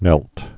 (nĕlt)